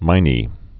(mīnē)